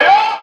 MZ Vox [Melo].wav